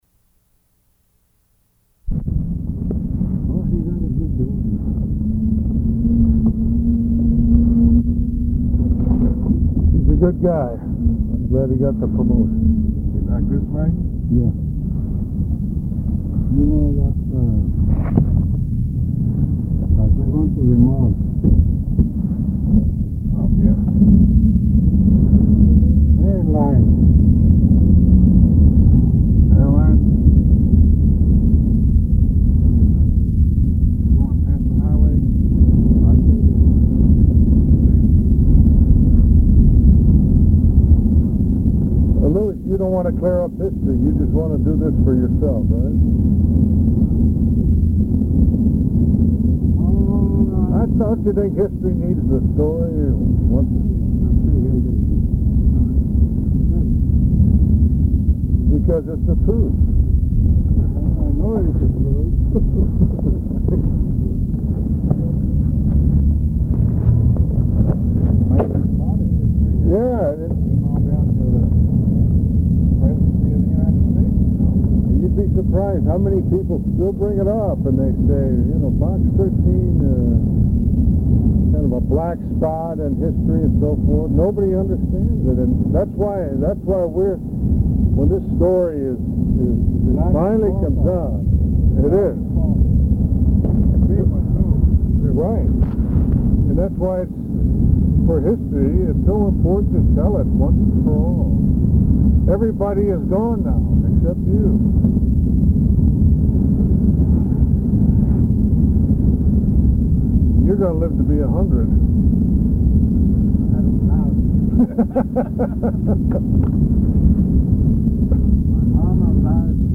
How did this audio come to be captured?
side B Archivist General Note Segment 1 very hard to hear, in a moving car. Segment 2 hard to hear.